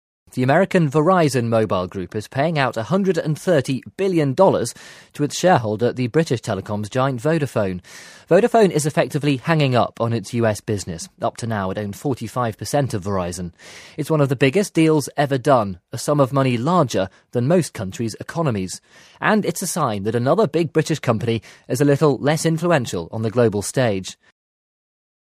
【英音模仿秀】天价业务 听力文件下载—在线英语听力室